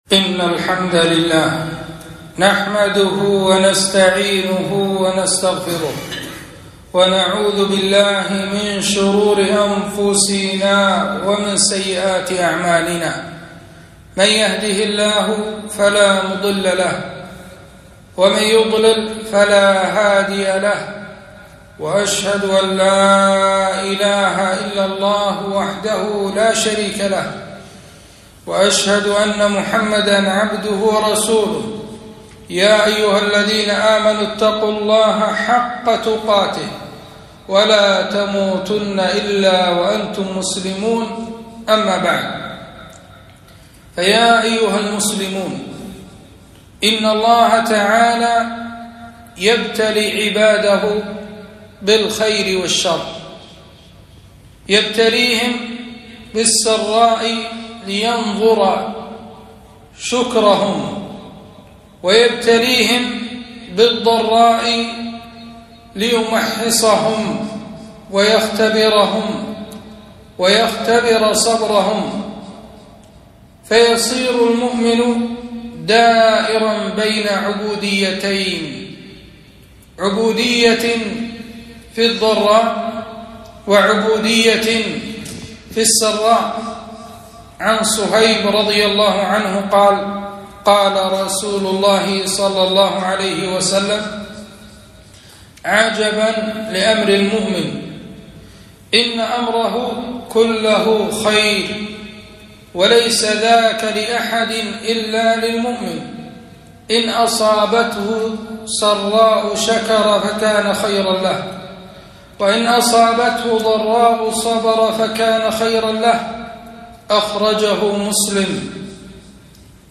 خطبة - (واصبر فإن الله لا يضيع أجر المحسنين) - دروس الكويت